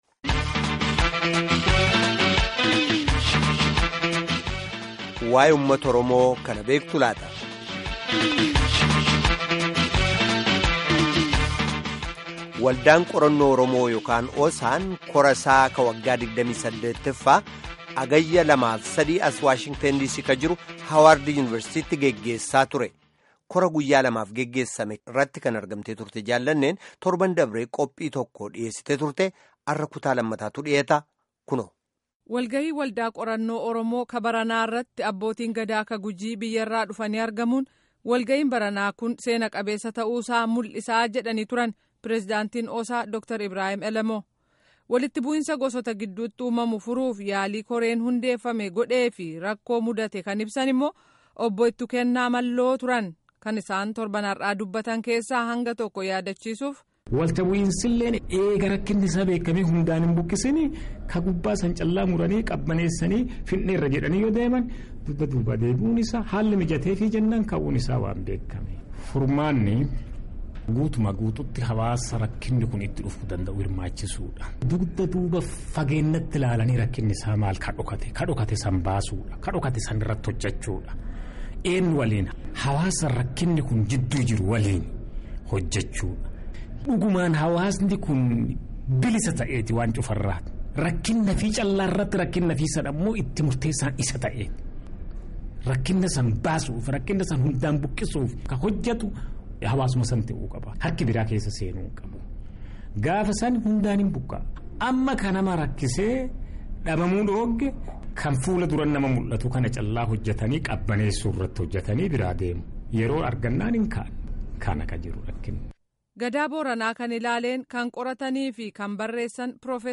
Gaaffii fi deebii geggeeffame kutaa 2ffaa caqasaa